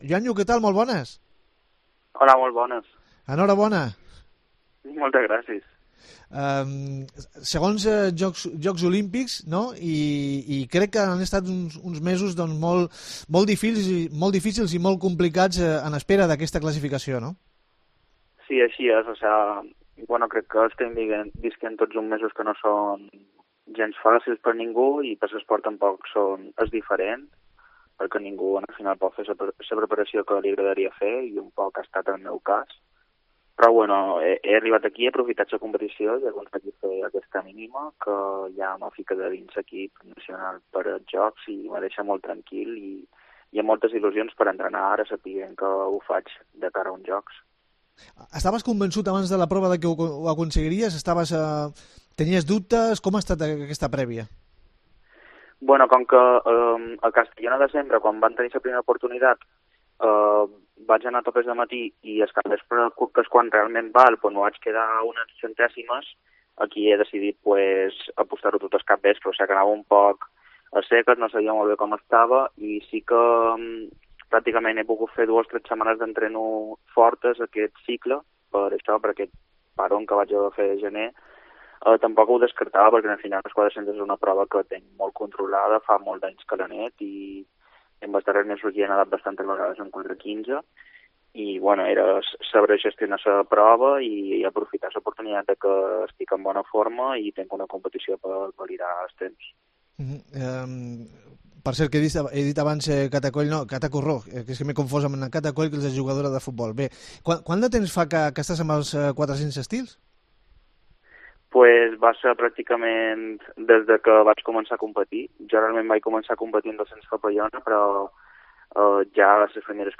Entrevista con Joan Lluis Pons